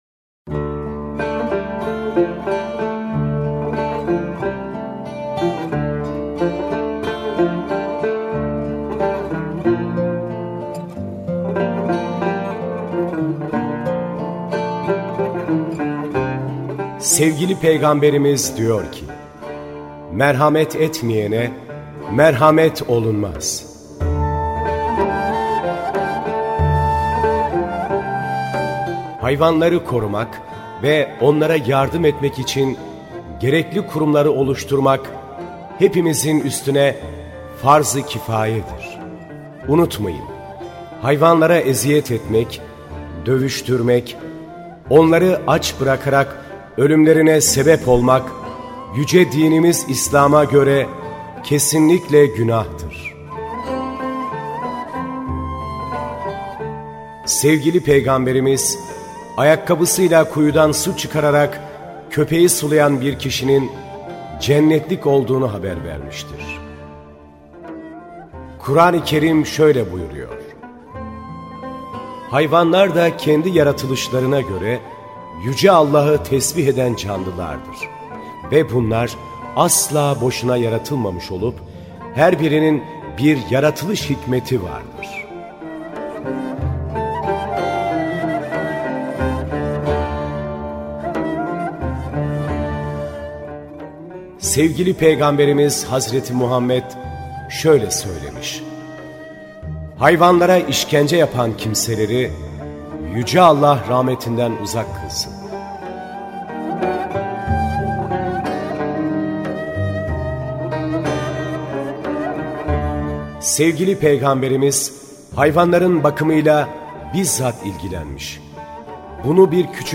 HAYTAP Anons Bir Kap Su Erkek sesi ile - DOHAYKO